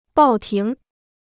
报亭 (bào tíng): Kiosk; Zeitungskiosk.